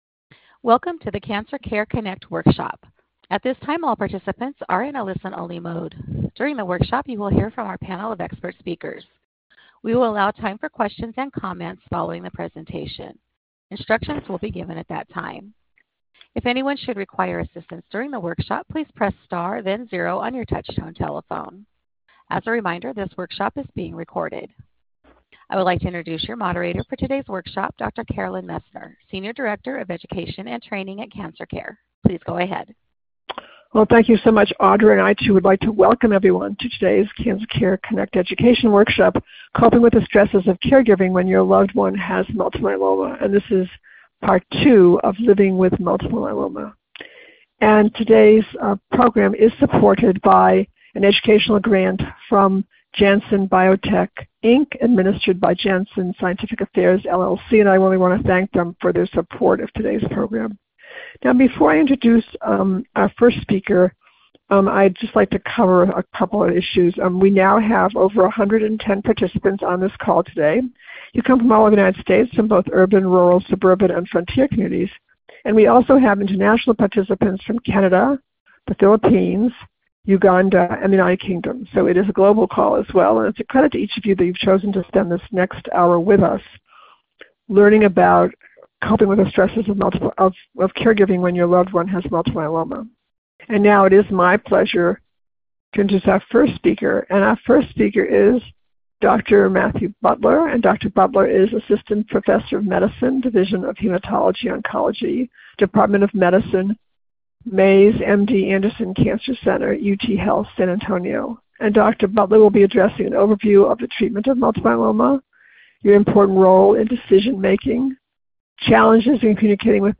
Questions for Our Panel of Experts
This workshop was originally recorded on April 03, 2025.